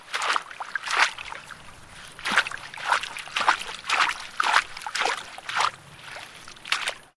Звуки болота
Шаги по болотной топи